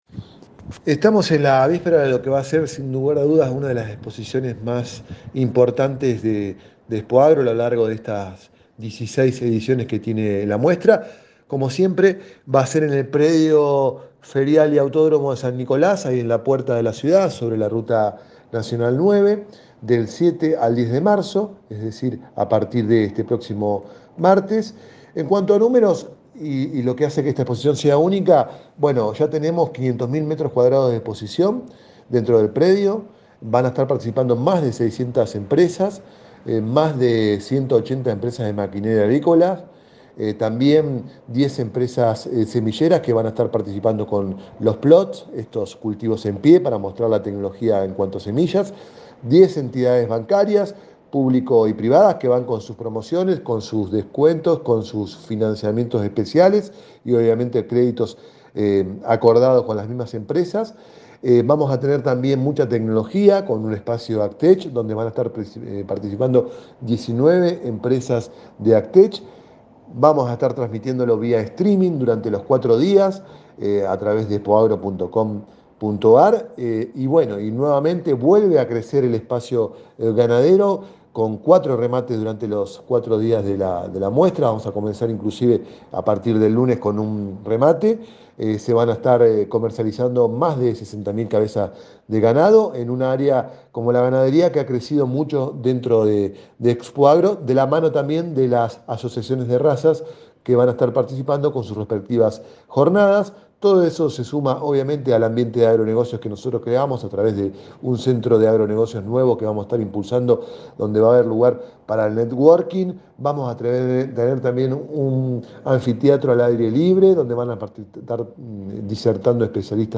en diálogo con El Campo Hoy